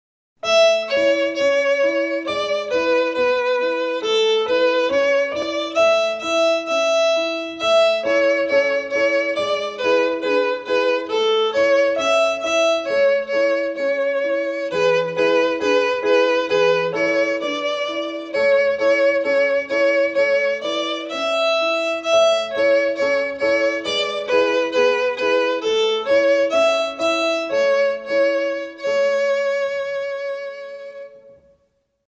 Children Song
Solo Recorder